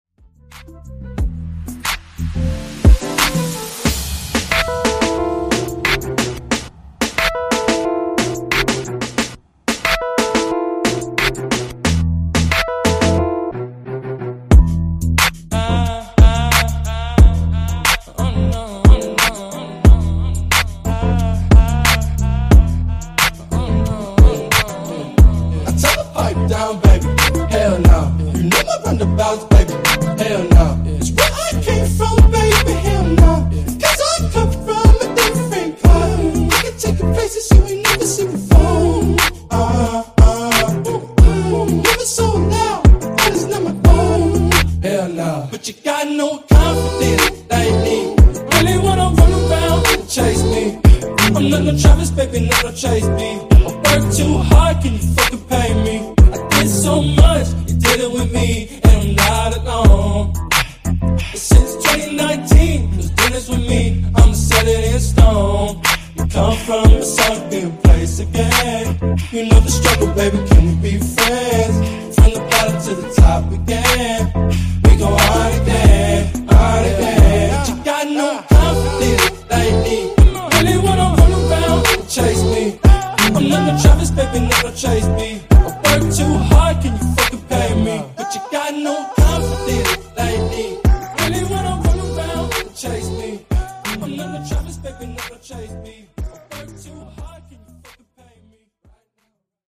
Genre: RE-DRUM Version: Dirty BPM: 120 Time